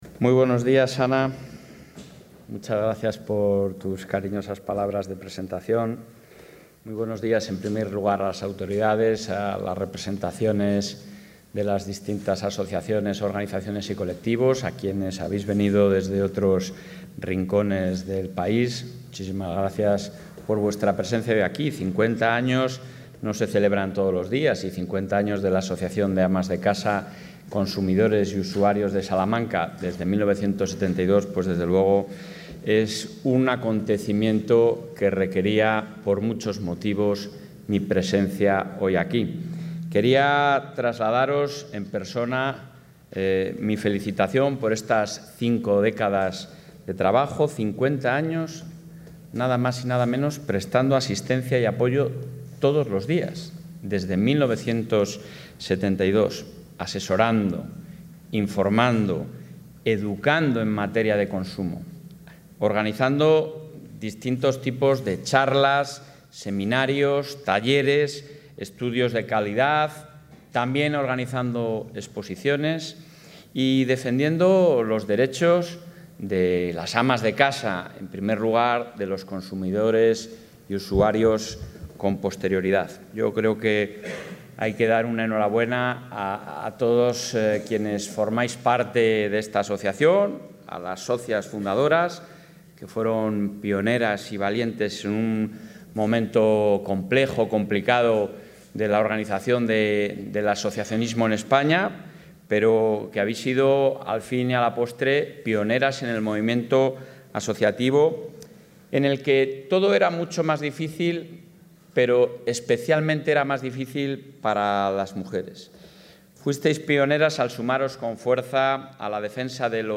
Intervención del presidente.
El presidente de la Junta de Castilla y León ha participado esta mañana en la celebración del 50 aniversario de la Asociación de Amas de Casa, Consumidores y Usuarios que lleva prestando asistencia y apoyo todos los días desde 1972